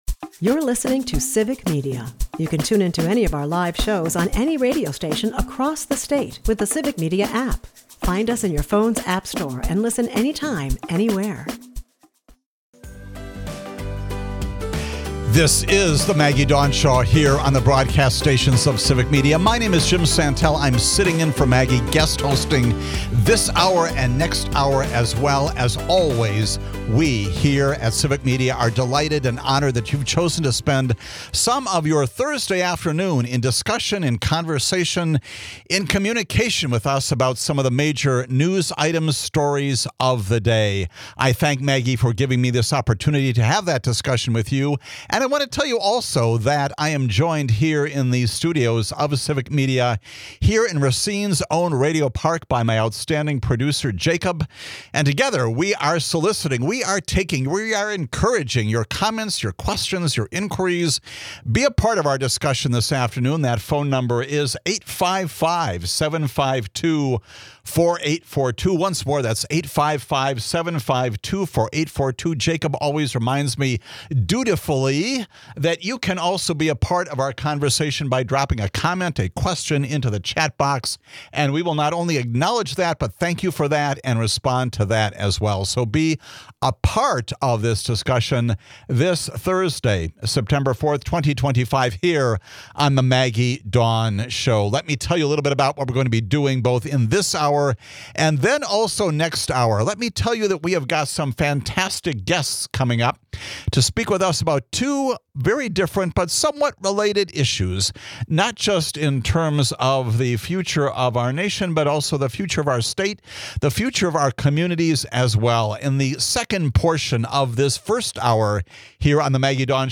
Later, Wauwatosa Mayor Dennis McBride joins to share his experience guiding the city through a thousand-year flood, emphasizing resilience and unexpected community support. McBride also reflects on his book, A City on the Edge , chronicling the turmoil of 2020—navigating the pandemic, confronting racial tensions, and reaffirming democratic principles in a time of crisis.